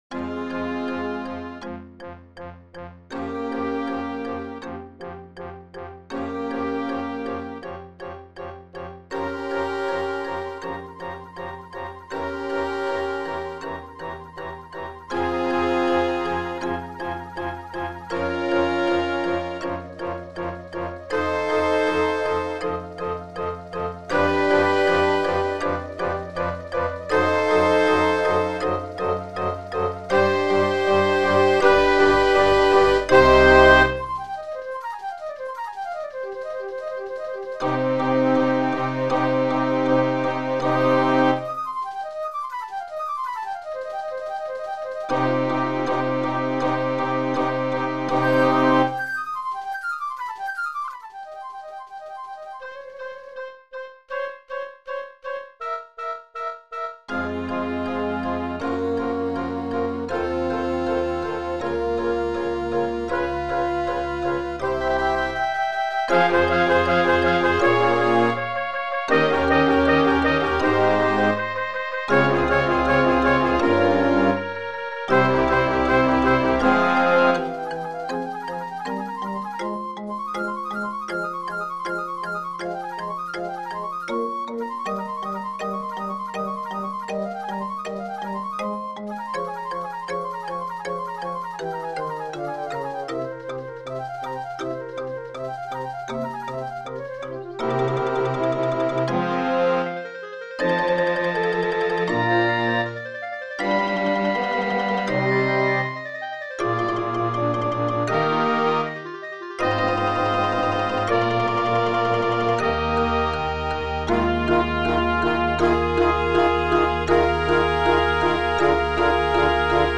Contrabas
Marimba ( 2 spelers)